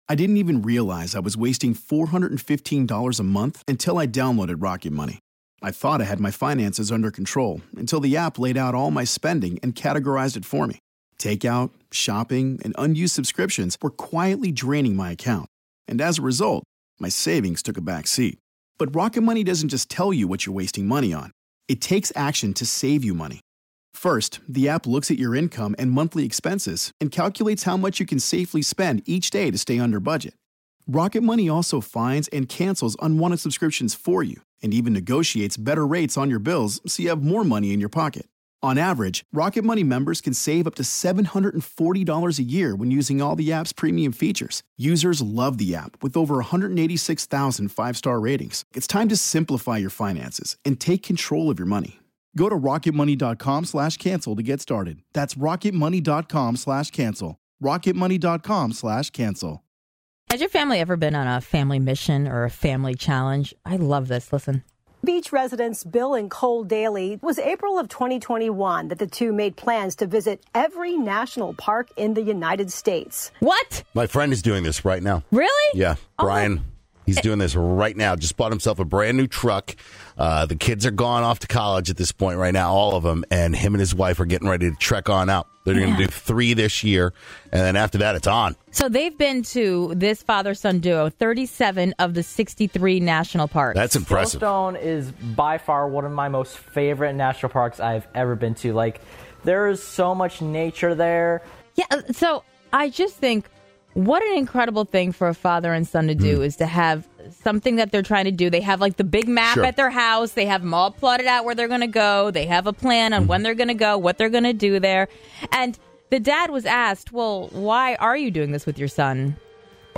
One family has a mission to visit all 67 National Parks in the US and there’s a very special reason for the father son! Some families called in and shared some pretty wild challenges!